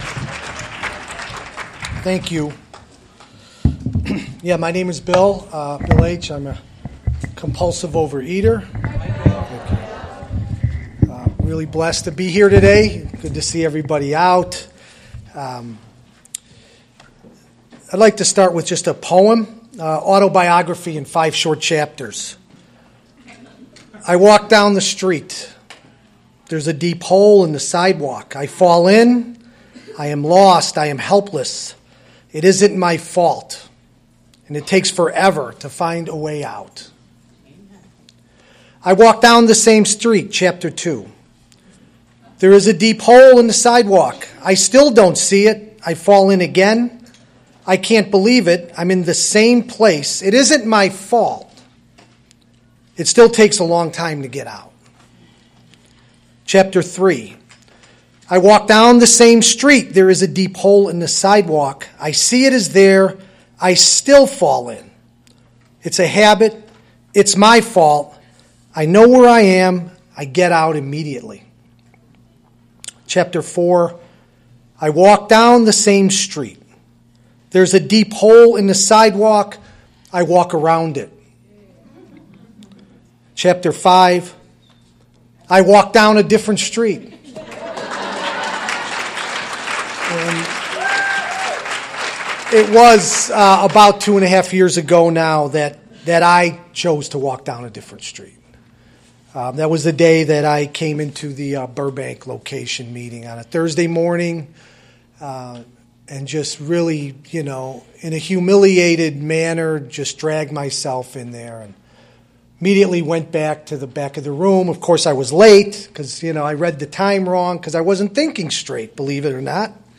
2024 OA Milwaukee Area Convention / “Extending Hand and Heart”…